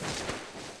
Index of /server/sound/npc/poisonzombie
foot_slide1.wav